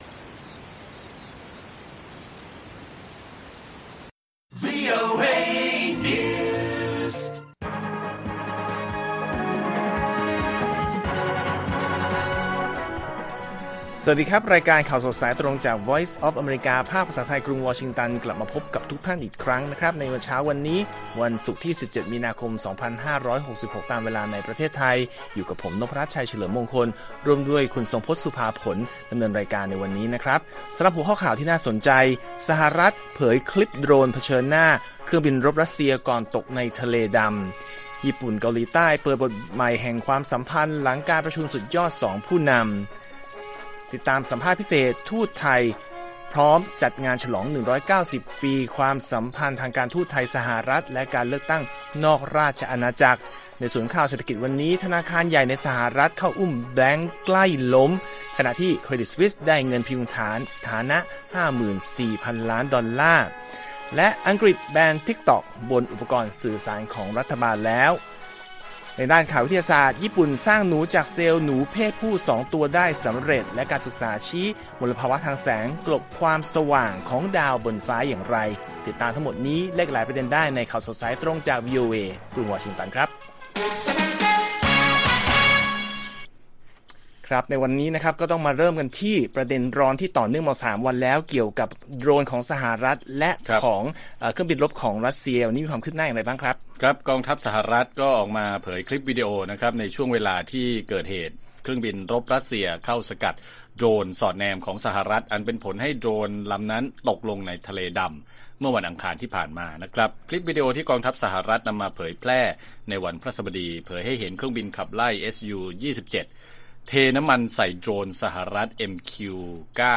ข่าวสดสายตรงจากวีโอเอไทย 6:30 – 7:00 น. 17 มี.ค. 2566